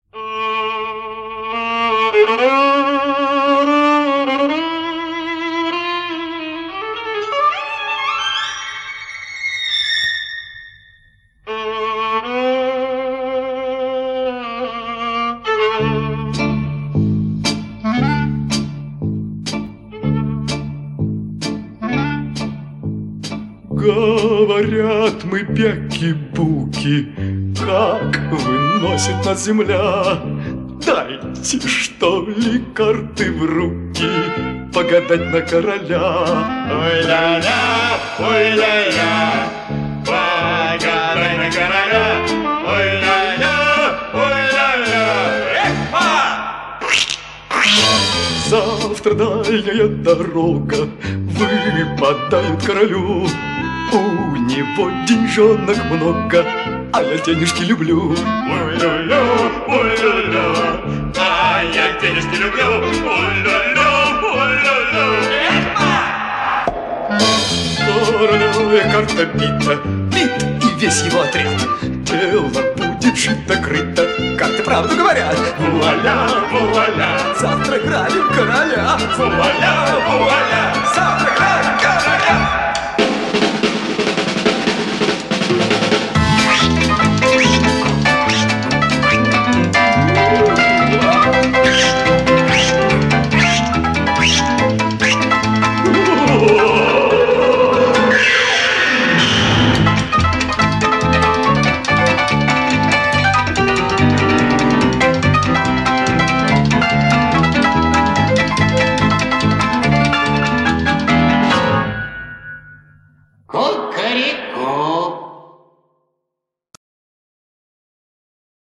песня из мультфильма